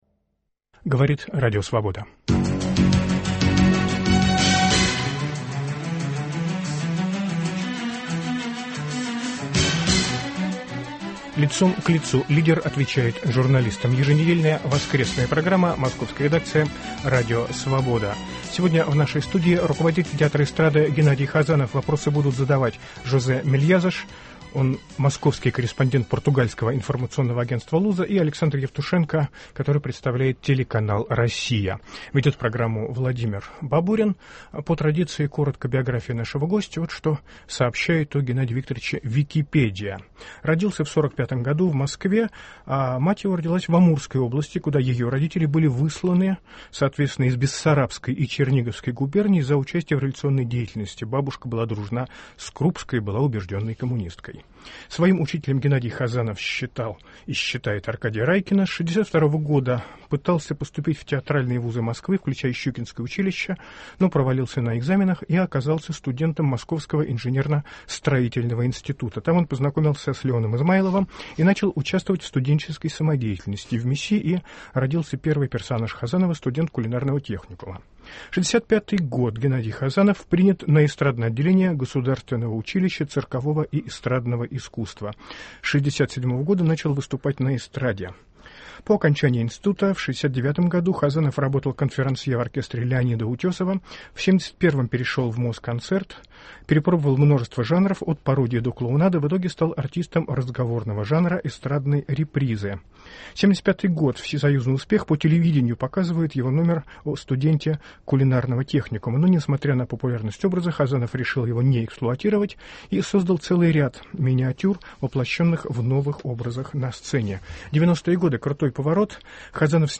Гость - художественный руководитель Московского Государственного Театра Эстрады, народный артист России Геннадий Хазанов.